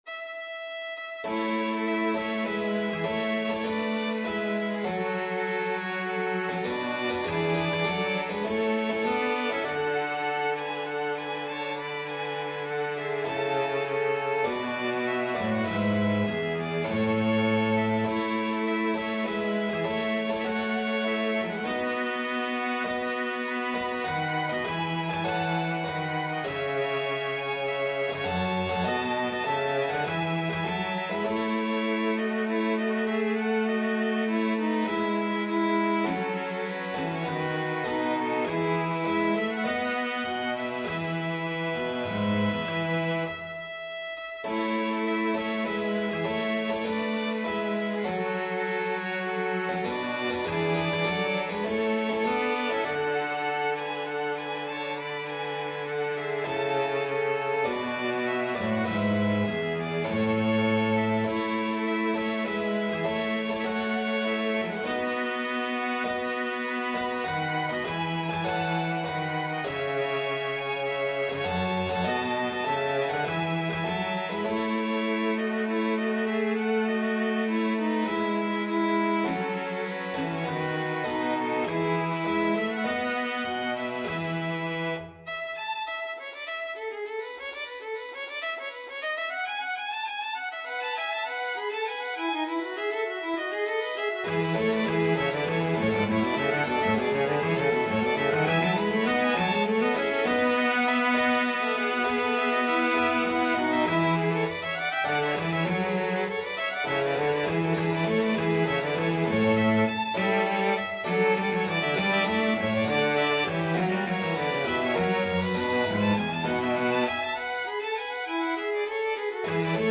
Violin 1 Violin 2 Cello Piano
Tempo Marking: ~ = 100 Overture
Score Key: A major (Sounding Pitch)
Time Signature: 4/4
Style: Classical